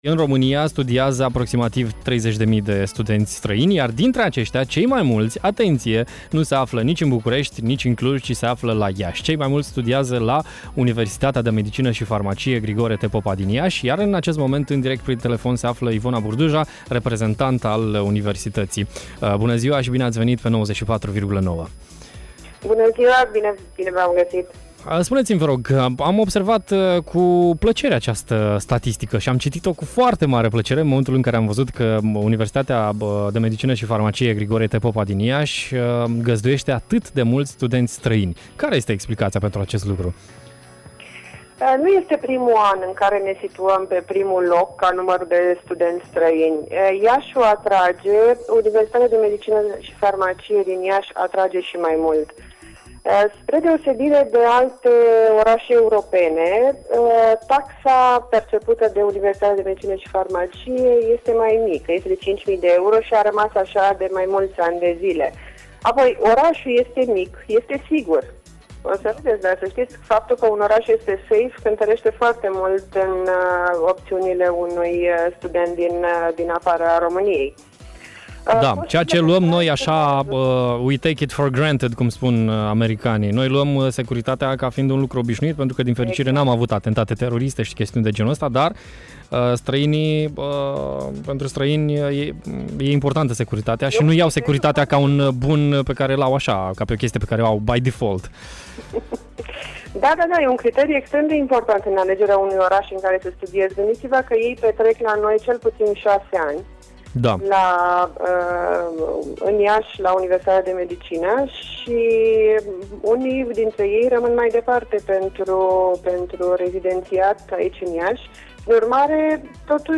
Mai multe detalii am aflat în direct la Radio Hit